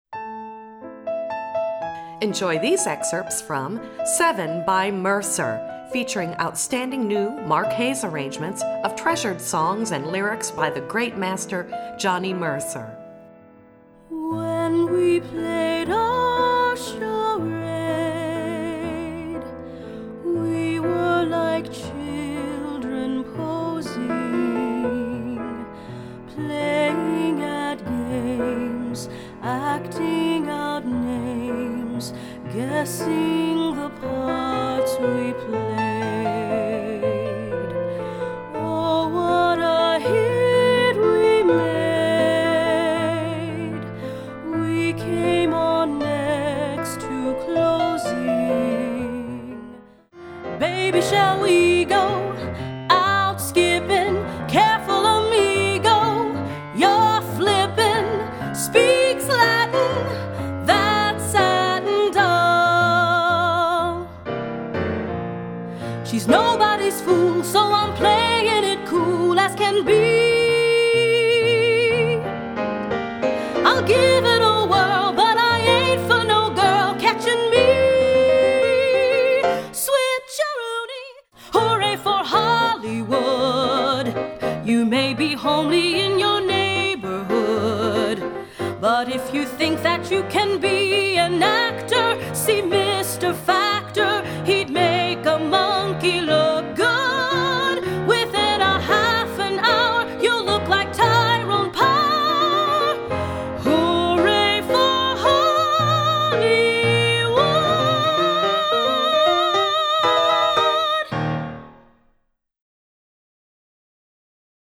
Voicing: Medium-Low Voice